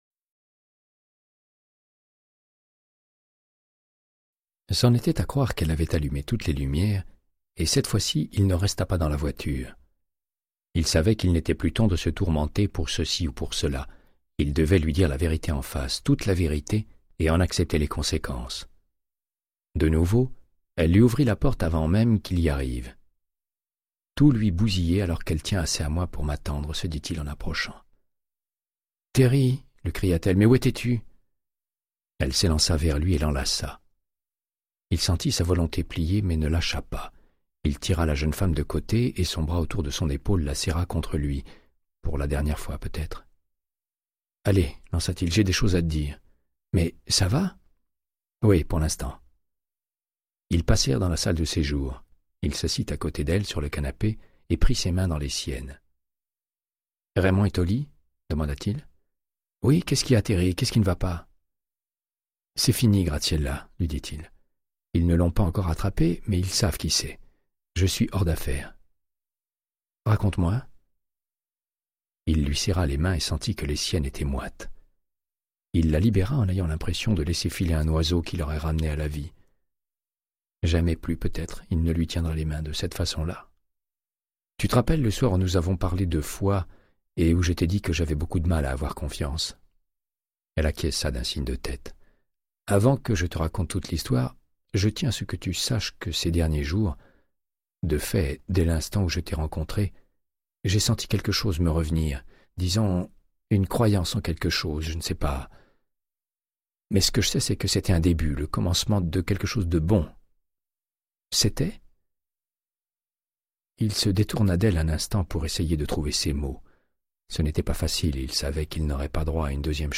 Audiobook = Créance de sang, de Michael Connelly